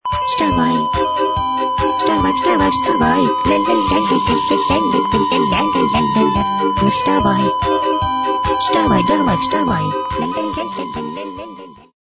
• Пример реалтона содержит искажения (писк).